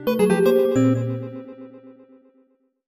jingle_chime_17_negative.wav